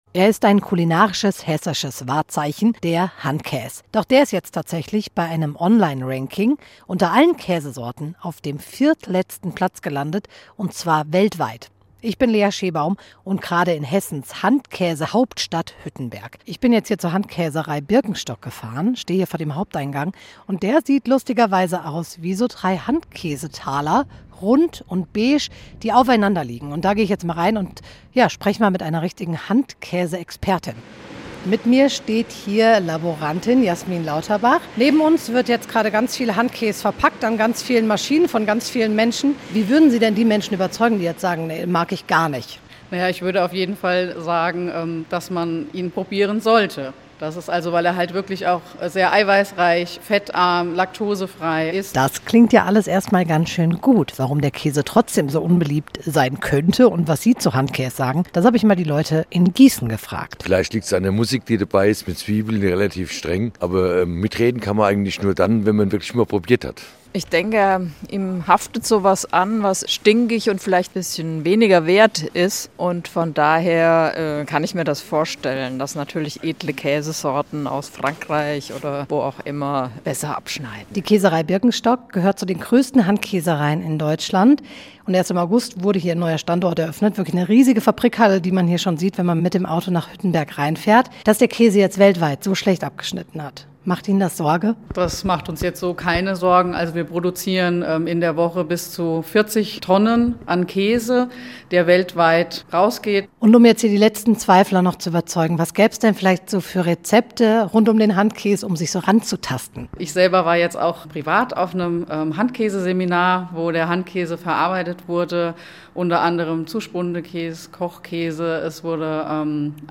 Mittags eine aktuelle Reportage des Studios Gießen für die Region.